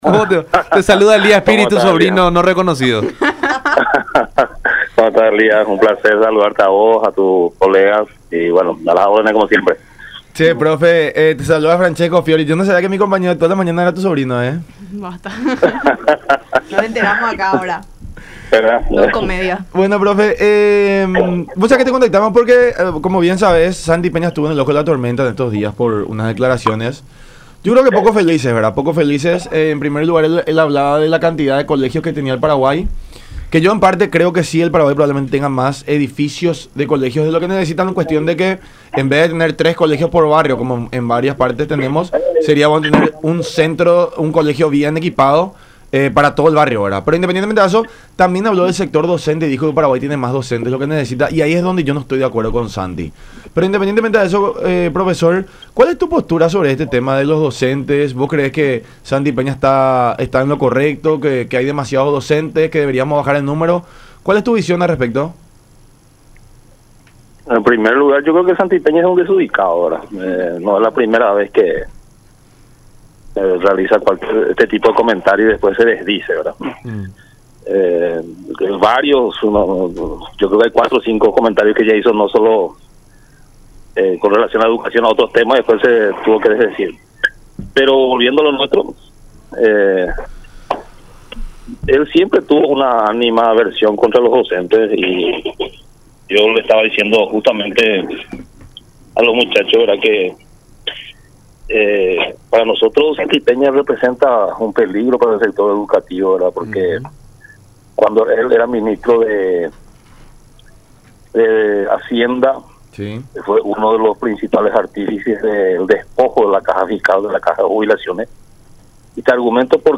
en diálogo con La Unión Hace La Fuerza a través de Unión TV y radio La Unión.